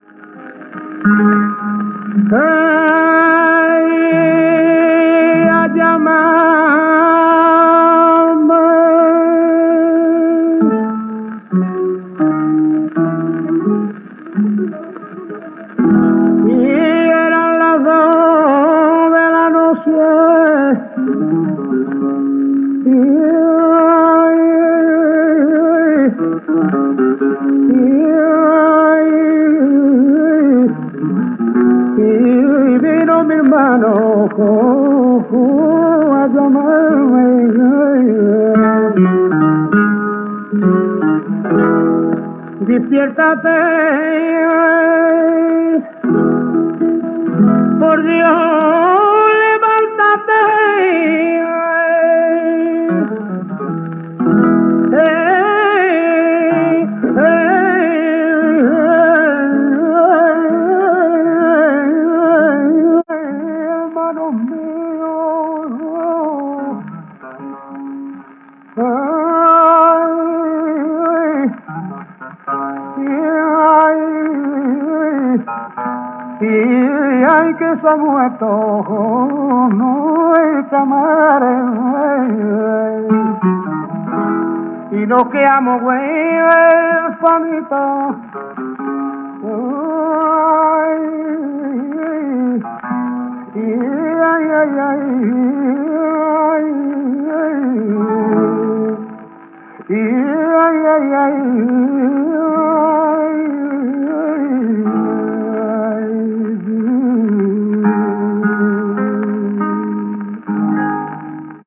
guitarra: